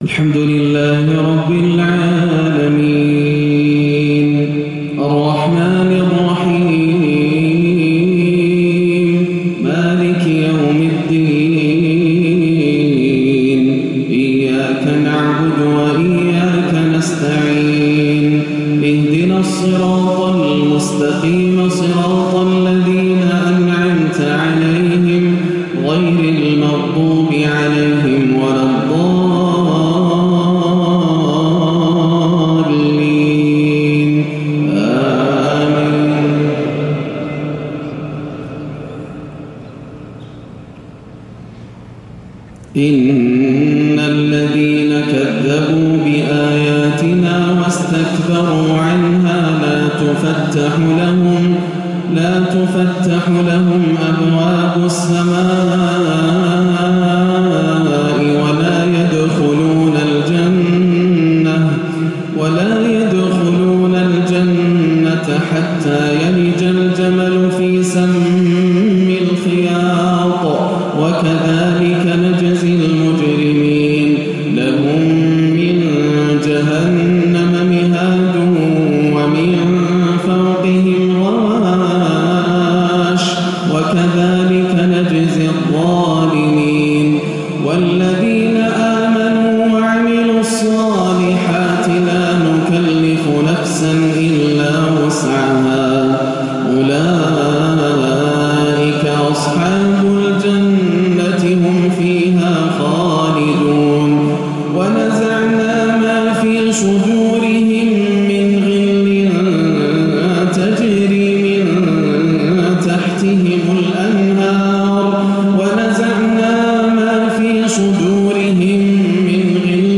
(ونادى أصحاب النار أصحاب الجنة أن أفيضوا علينا من الماء)عشائية الشيخ ياسر الدوسري 1-1-1438هـ > عام 1438 > الفروض - تلاوات ياسر الدوسري